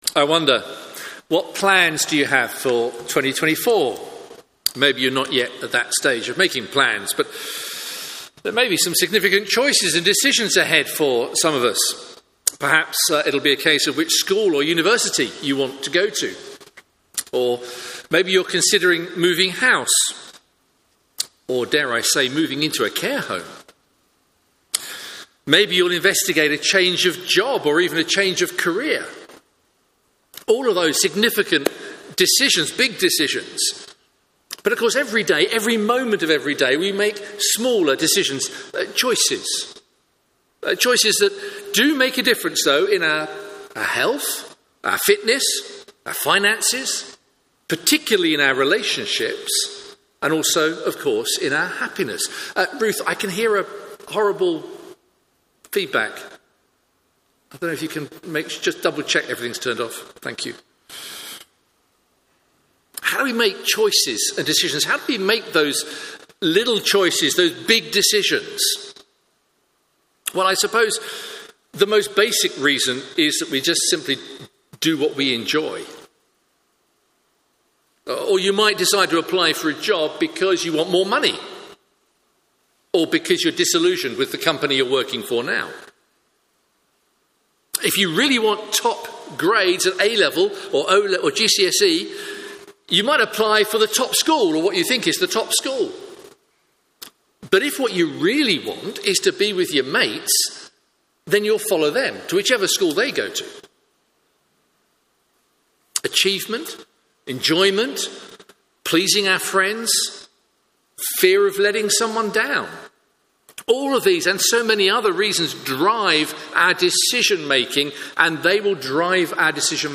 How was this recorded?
Media for Morning Service on Sun 31st Dec 2023 10:30 Speaker